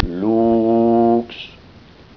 Bu yazıda ayrıca ritüel sırasında zikredilen bazı sözlerin ses dosyaları vardır ve mavi gözüken bu kelimeleri tıklandığında nasıl telaffuz edildikleri sesli bir biçimde gösterilecektir.